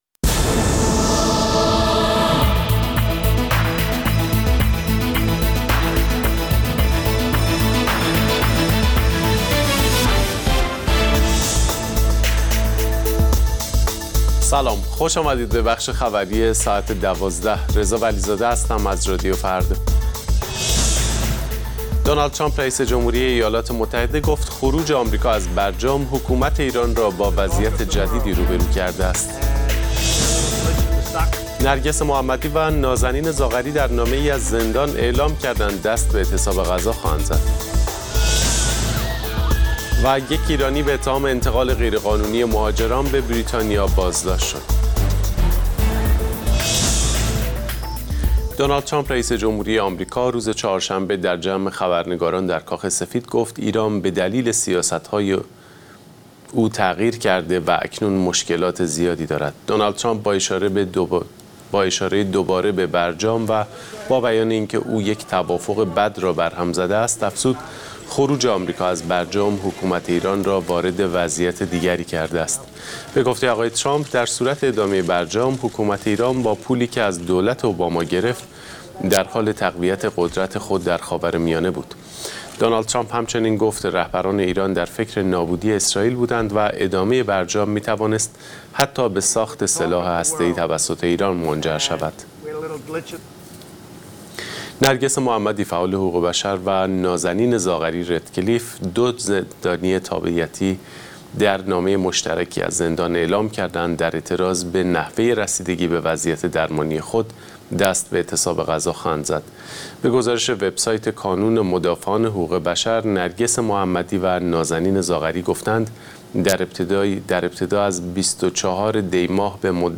اخبار رادیو فردا، ساعت ۱۲:۰۰